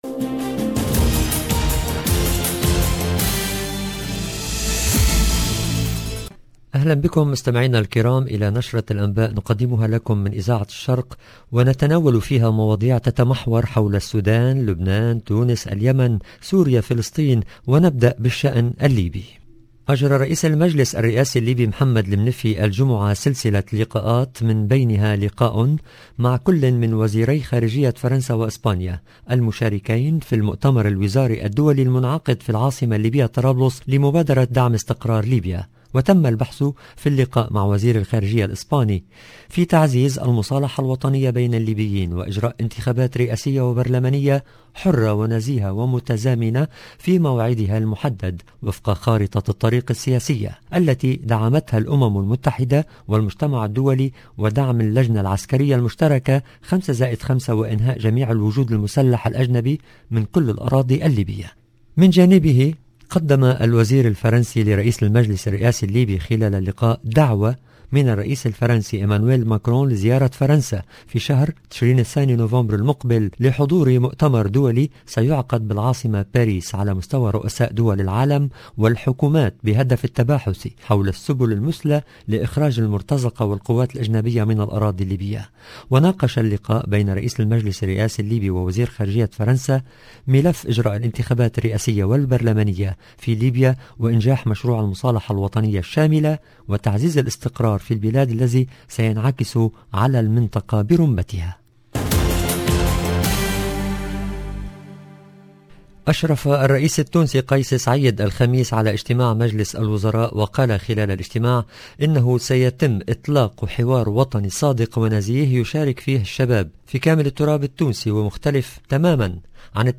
LE JOURNAL DU SOIR EN LANGUE ARABE DU 22/10/21